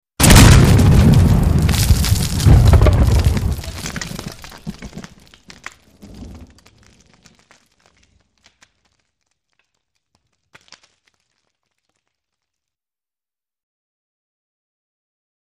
Grenade- Defensive with Debris Ver 2